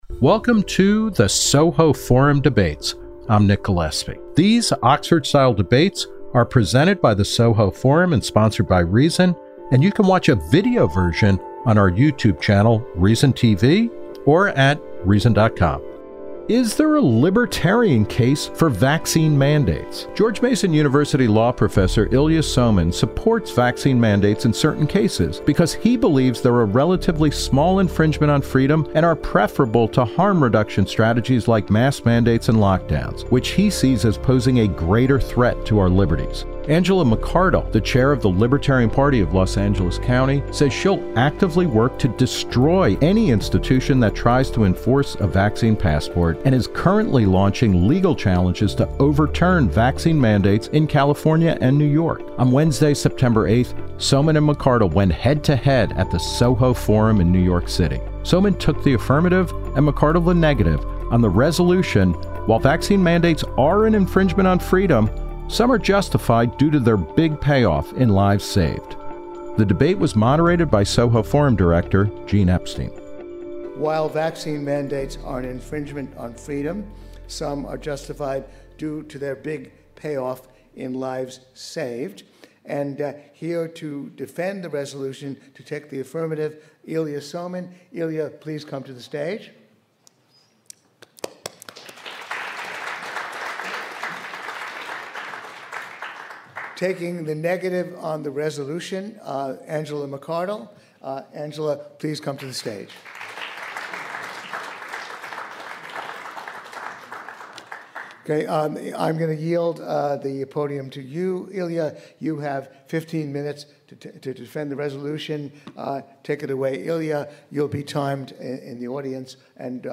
Two Libertarians Debate Vaccine Mandates